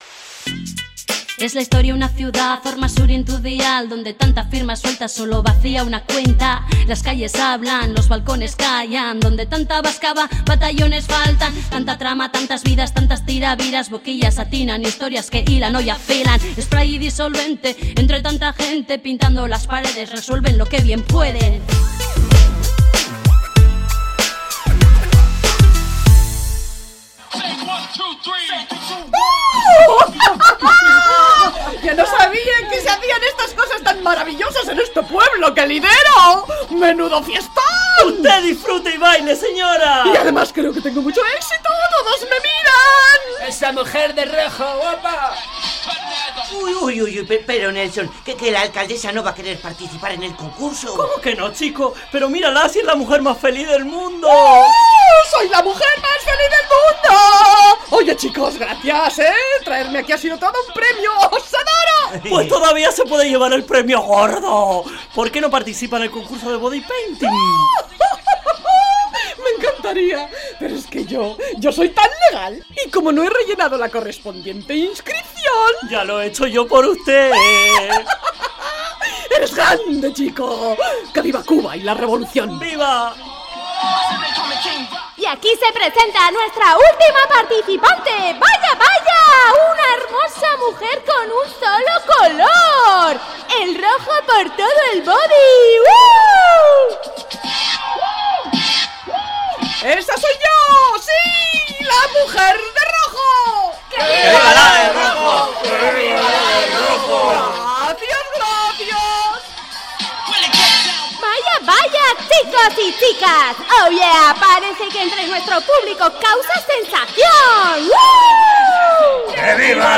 Entrega número 35 de la Radio-Ficción “Spray & Disolvente”